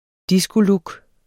Udtale [ ˈdisgoˌlug ]